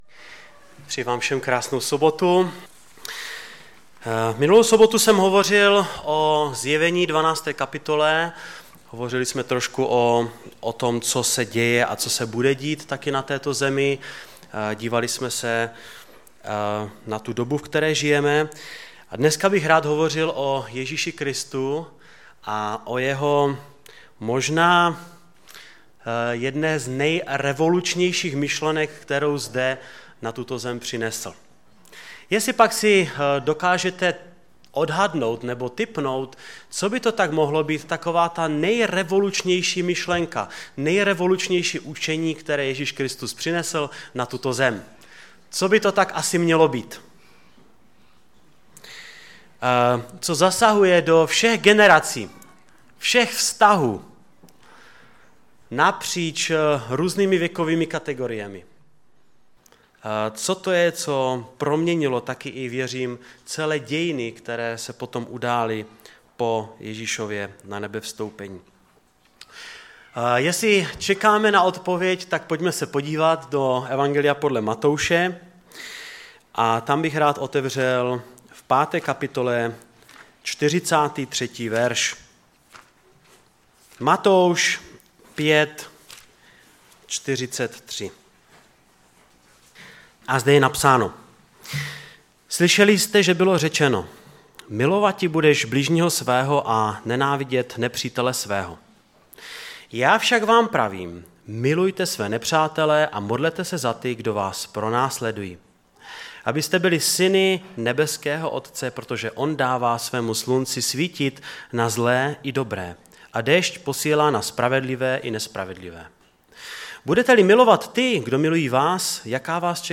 Kázání
ve sboře Ostrava-Radvanice v rámci Památky Večeře Páně.